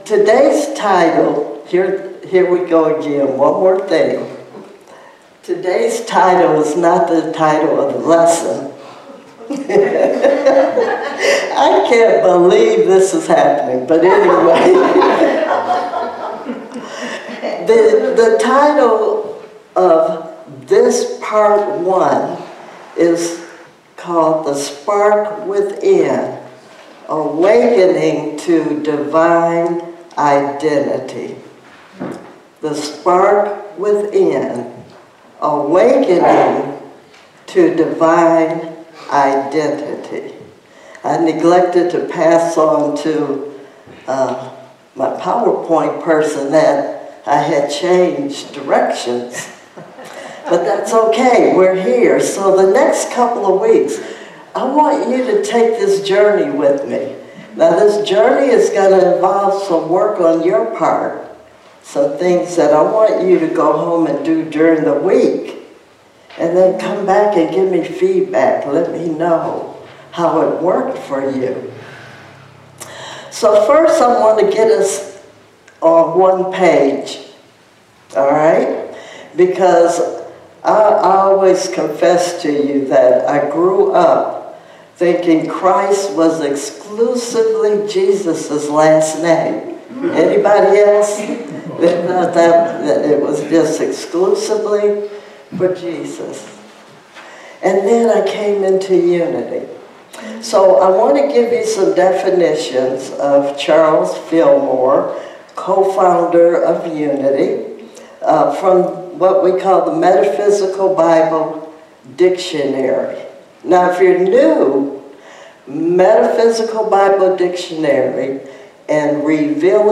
Sermons 2025